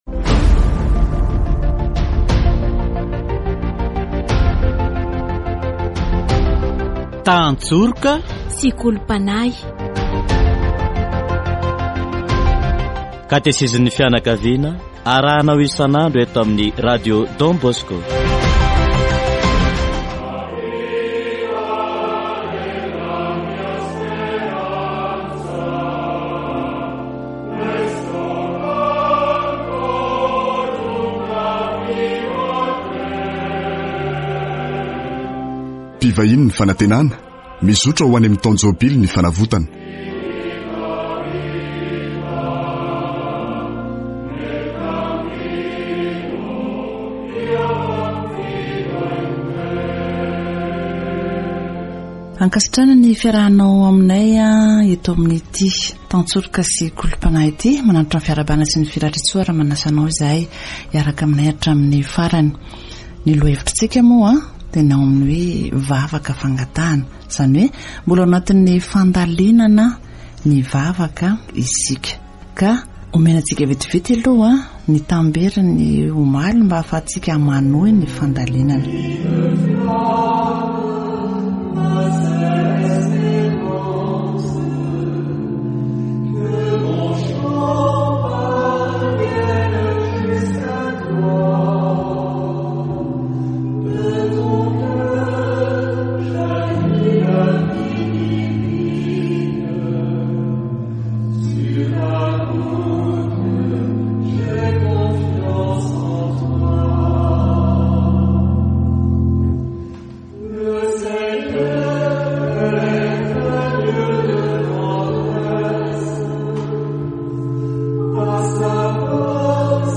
Ao amin’ny “Rainay” isika no mivavaka mba hahazo famelan-keloka – izay fanao isan’andro; mila famelan-keloka foana isika - dia ny fiadanana eo amin'ny fifandraisantsika; ary farany, mba hanampiany antsika amin’ny fakam-panahy sy hanafaka antsika amin’ny ratsy. Katesizy momba ny vavaka fangatahana